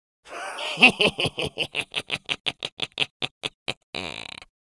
The sound of laughing witch - Eğitim Materyalleri - Slaytyerim Slaytlar